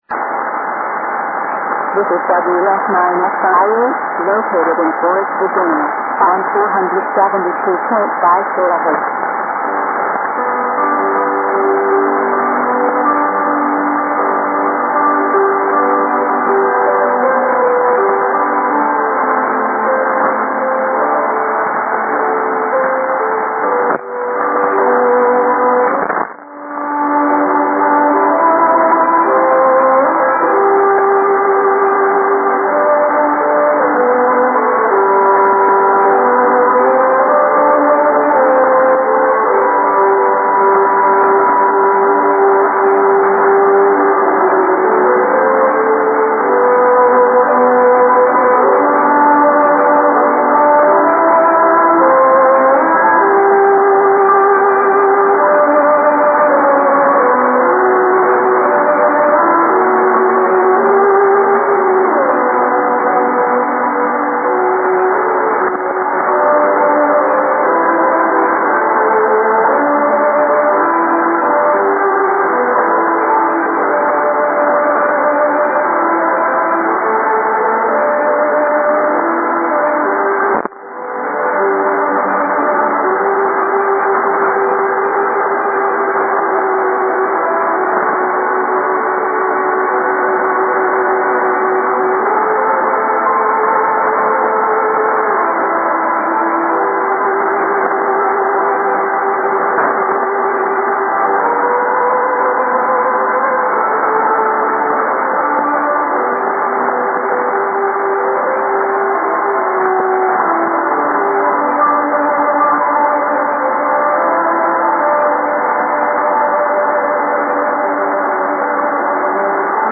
Suddenly, in the headphones you hear a human voice and music.